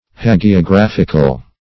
hagiographical \ha`gi*o*graph"ic*al\(h[a^]`g[-e]*[o^]*gr[.a]f"[i^]k*al)
hagiographical.mp3